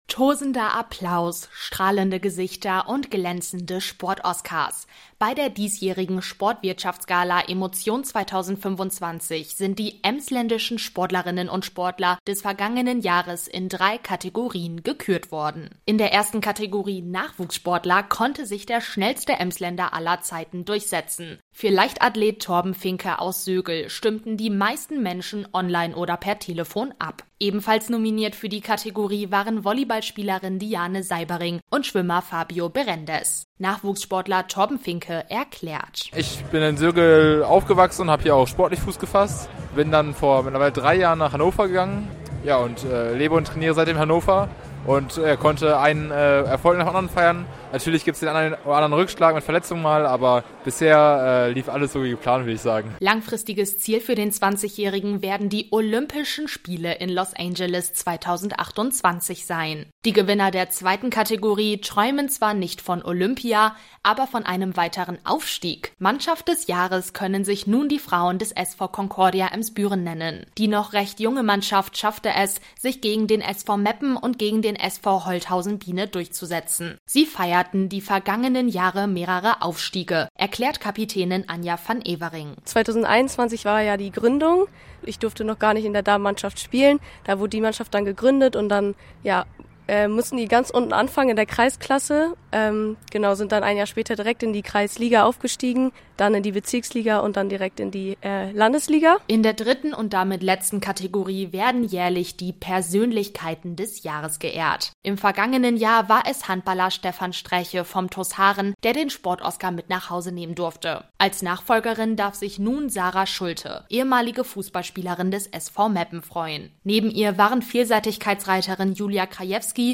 In den drei Kategorien Nachwuchssportler-, Mannschaft-, und Persönlichkeit des Jahres dürfen sich die Gewinner über einen Sport-Oscar freuen. Dieses Jahr fand die Sport-Gala unter dem Motto „Emotion 2025“ im Autohaus Bartels in Sögel statt.